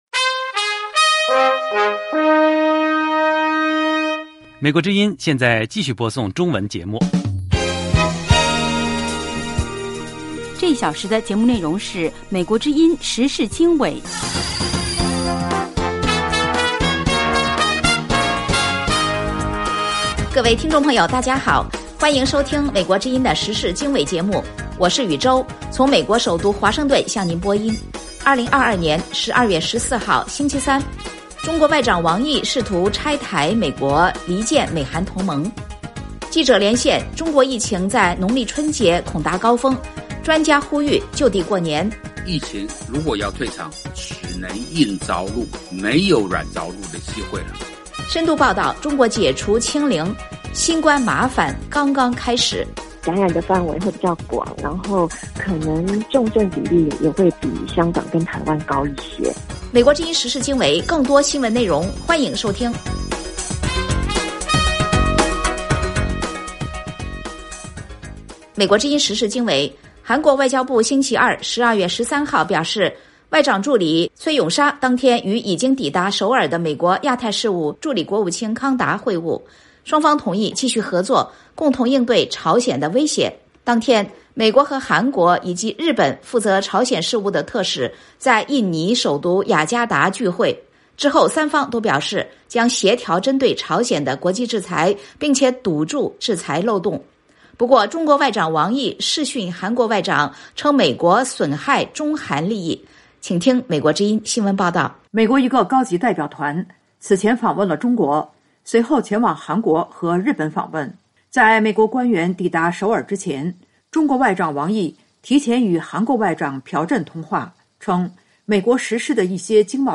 时事经纬(2022年12月14日)：1/中国外长王毅试图拆台美国，离间美韩同盟。2/记者连线：中国疫情农历春节恐达高峰 专家呼吁“就地过年”。3/深度报道：中国解除清零，新冠麻烦刚刚开始。